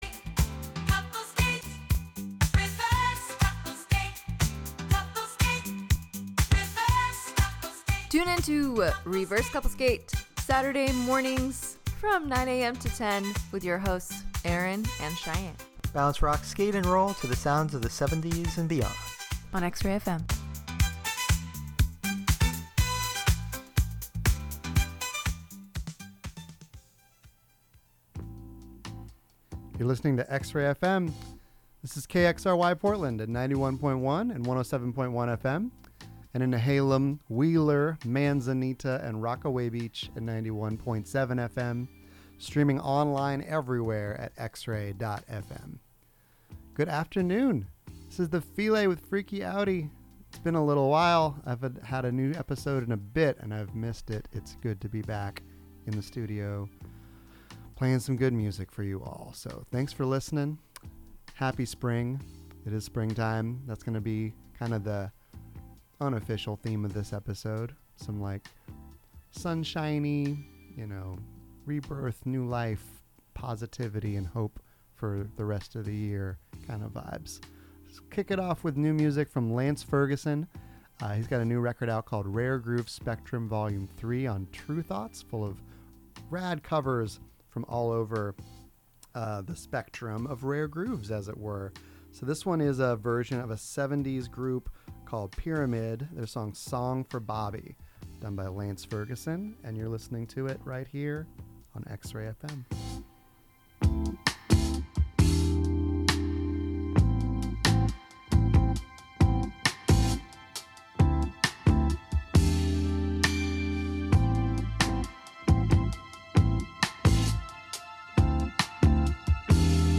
New and newly unearthed jams from all over to put you in a dancing mood.